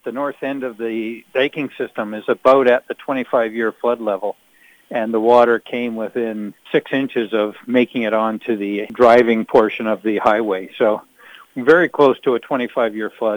North Cowichan Mayor, Jon Lefebure says without flood prevention infrastructure, flooding would have been catastrophic.